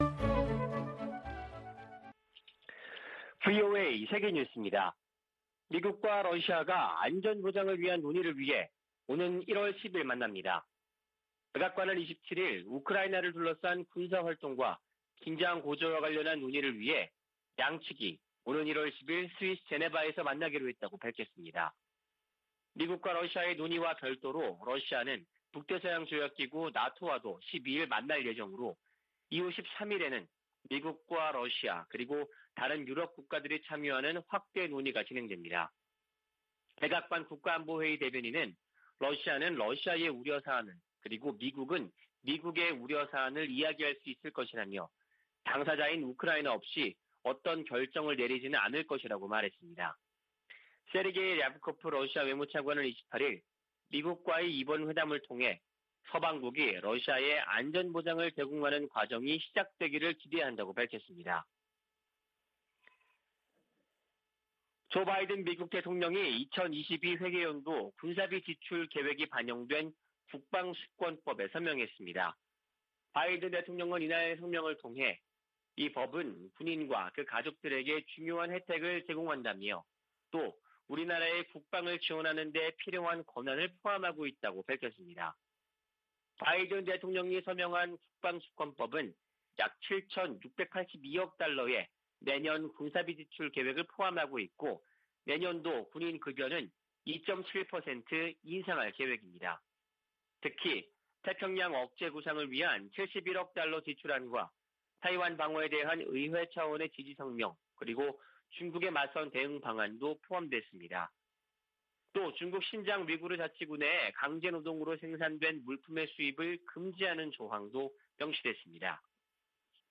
VOA 한국어 아침 뉴스 프로그램 '워싱턴 뉴스 광장' 2021년 12월 29일 방송입니다. 북한이 27일 김정은 국무위원장 주재로 올 들어 네번째 노동당 전원회의를 개최했습니다. 조 바이든 미국 행정부는 출범 첫 해 외교를 강조하며 북한에 여러 차례 손을 내밀었지만 성과를 거두진 못했습니다.